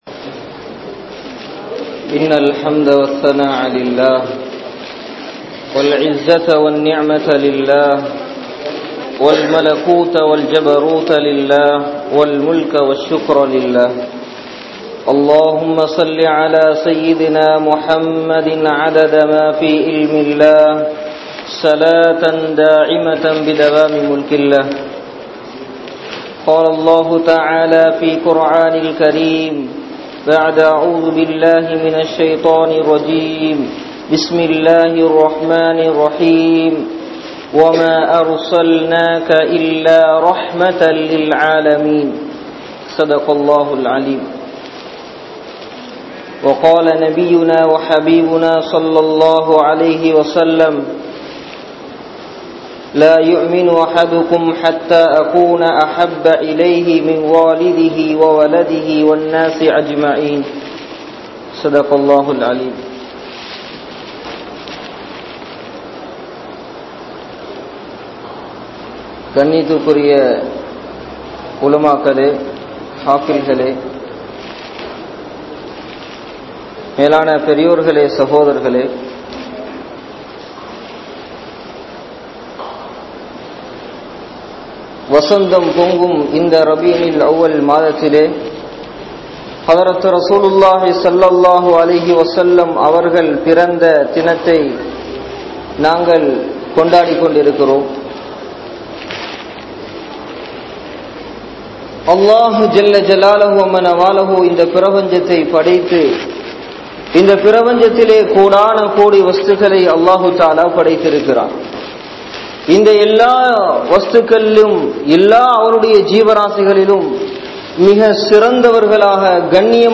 Yaar Intha Muhammathu Nabi(SAW)Avarhal (யார் இந்த முஹம்மது நபி(ஸல்)அவர்கள்) | Audio Bayans | All Ceylon Muslim Youth Community | Addalaichenai